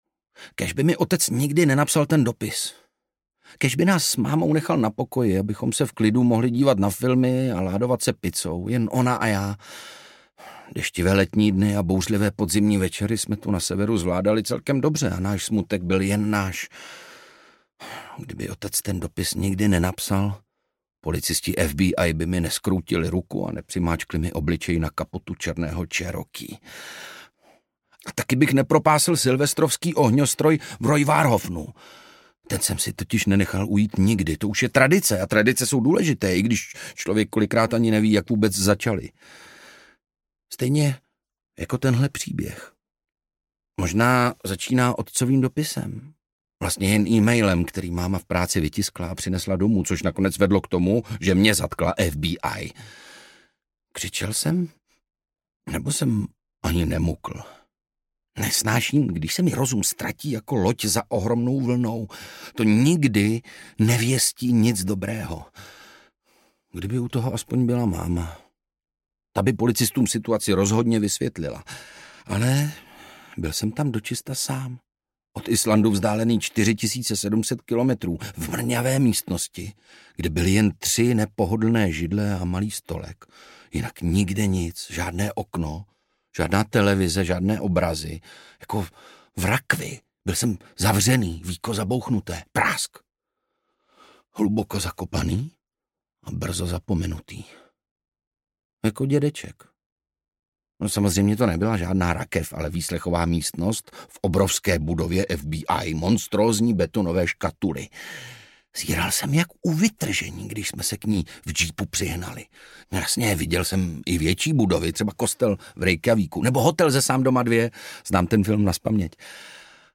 Kalmann a spící hora audiokniha
Ukázka z knihy
Čte Ondřej Brousek.
Vyrobilo studio Soundguru.